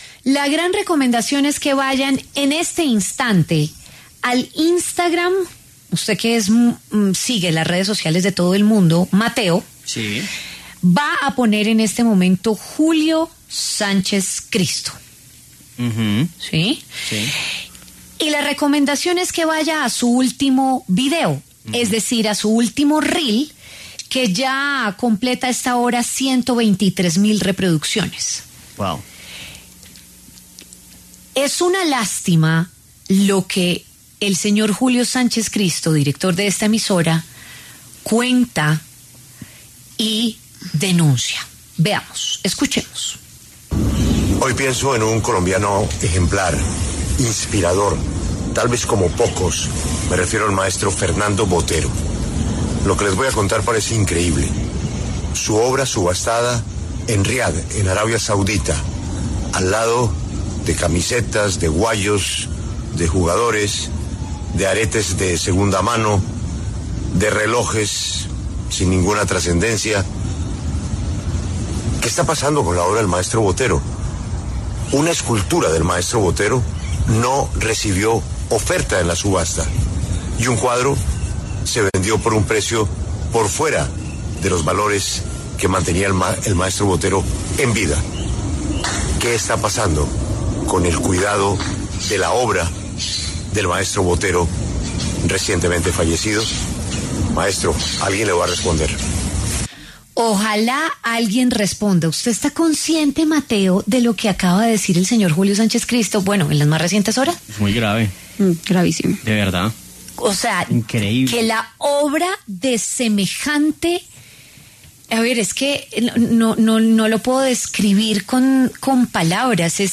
Julio Sánchez Cristo, director de La W, reveló que un cuadro del maestro Fernando Botero se subastó junto a objetos sin valor.